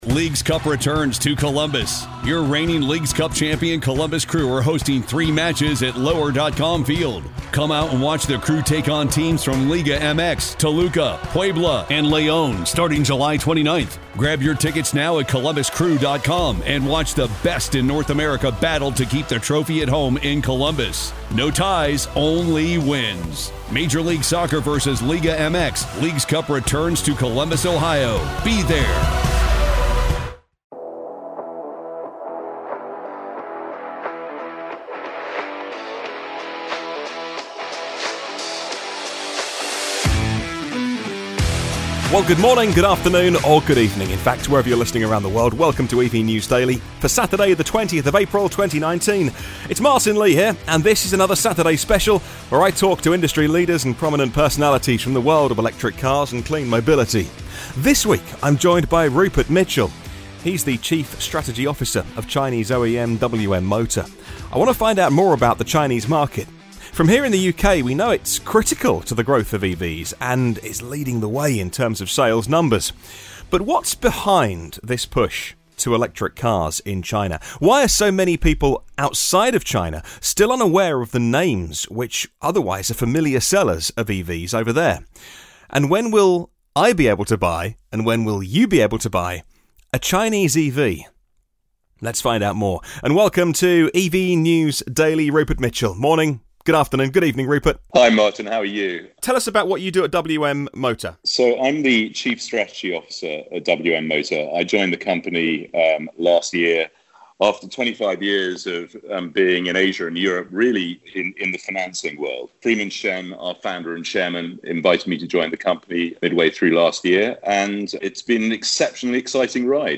20 Apr 2019 | Saturday Special: China In 2019 – Live From Shanghai With WM Motor